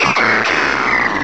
sovereignx/sound/direct_sound_samples/cries/passimian.aif at master